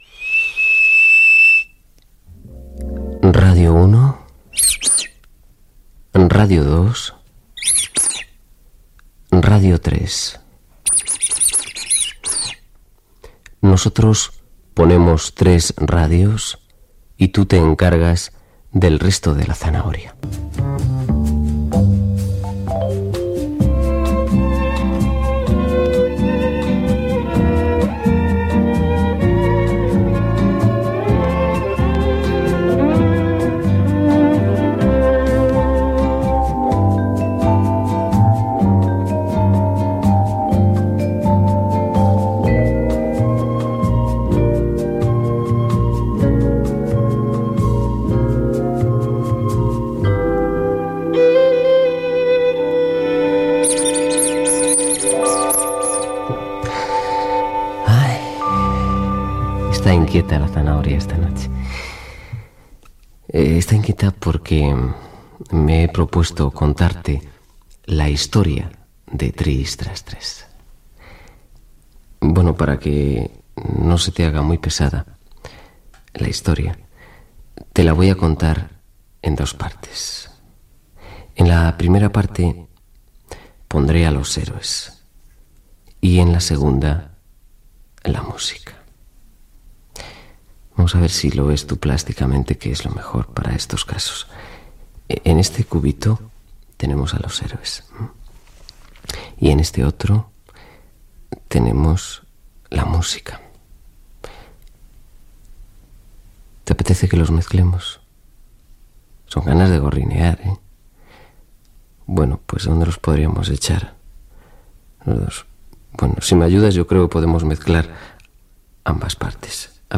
Últim programa. Esment a Radio 1, Radio 2 i Radio 3. Tema musical, la història del programa "Tris, tras, tres" Gènere radiofònic Entreteniment